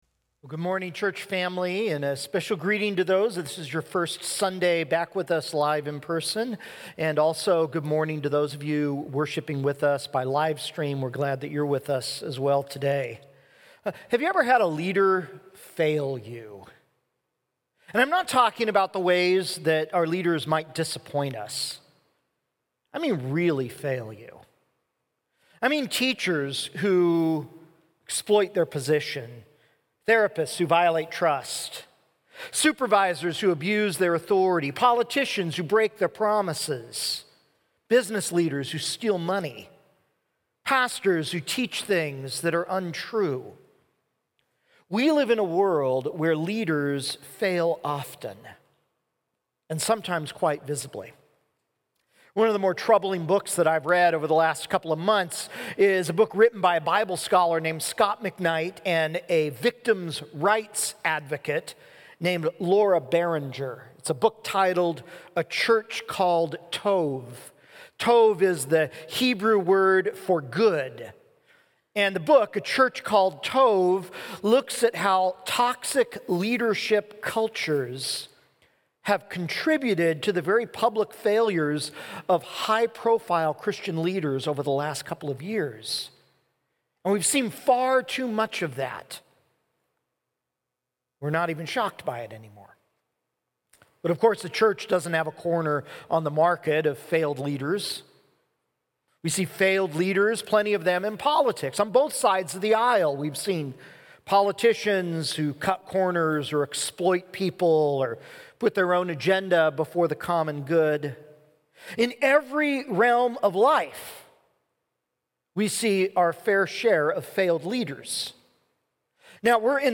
June 13, 2021 – Micah: When Our Leaders Fail (Sermon Only) – Glenkirk Church